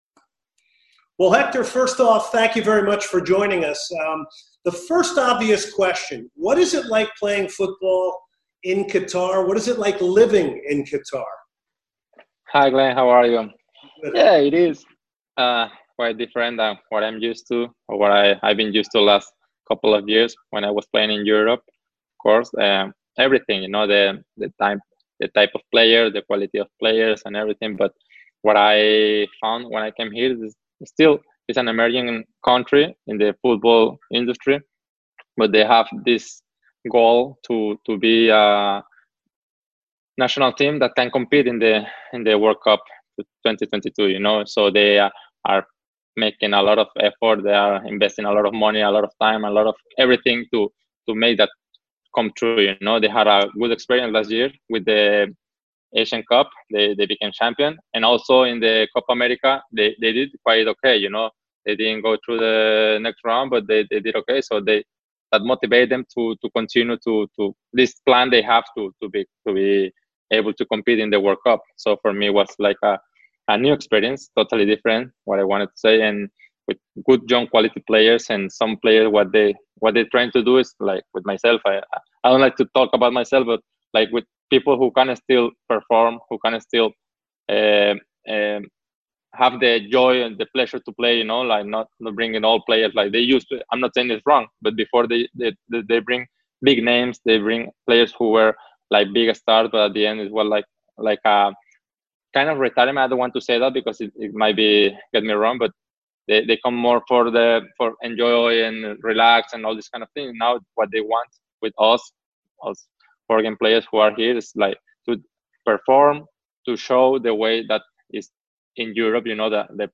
Hector Moreno Joins Soccer Matters - Full Interview